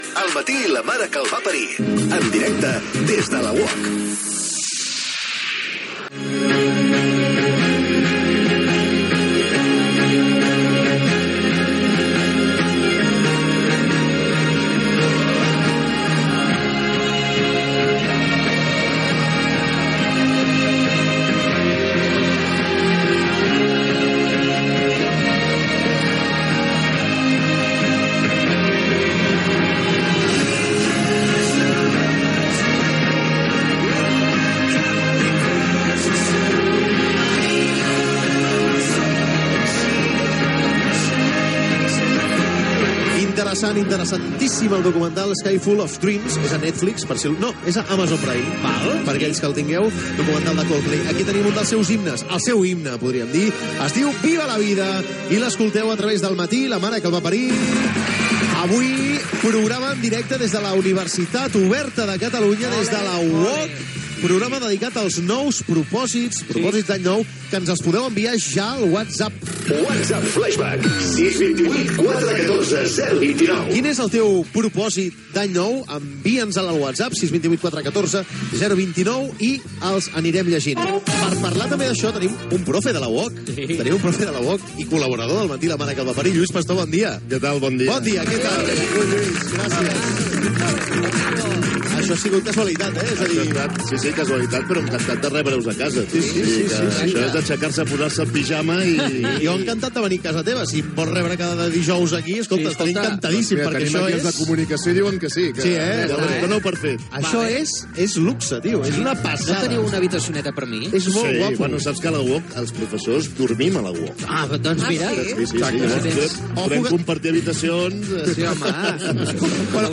Indiactiu del programa, tema musical, inici de l'hora del programa fet des de la seu de la Universitat Oberta de Catalunya, a Barcelona
Entreteniment